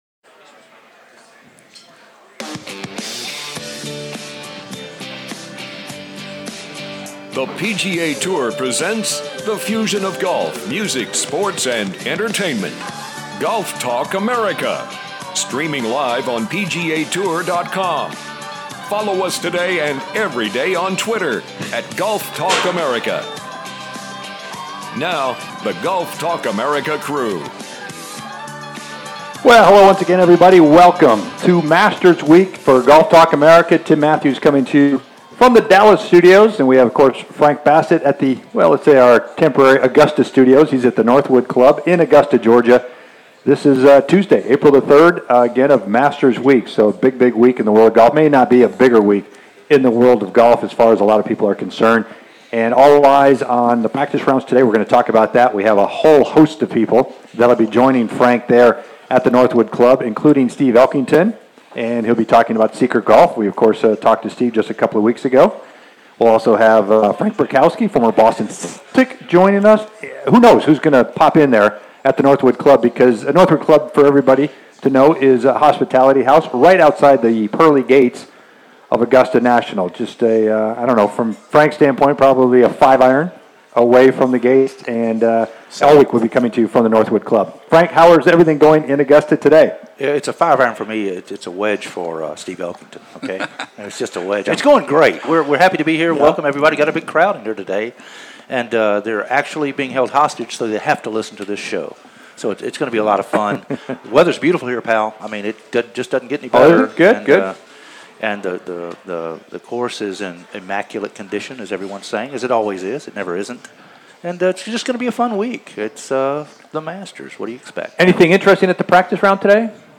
Day 2 "LIVE" from The Masters